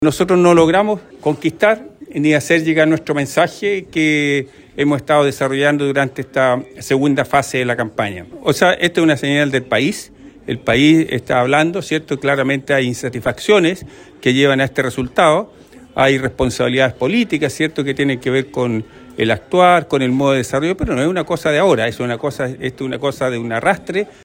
Panorama duro por el que el senador electo militante del PPD y coordinador regional de la campaña de Jara, Ricardo Celis, hizo un mea culpa, señalando que el mensaje que pretendían entregar, no llegaron a la ciudadanía y habló de responsabilidades políticas.